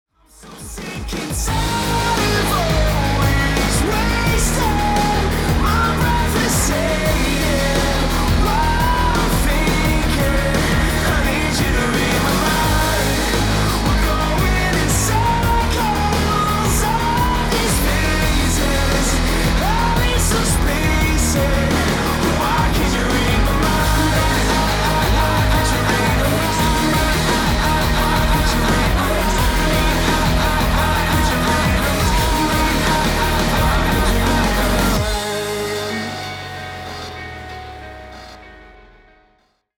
Kategória: Rock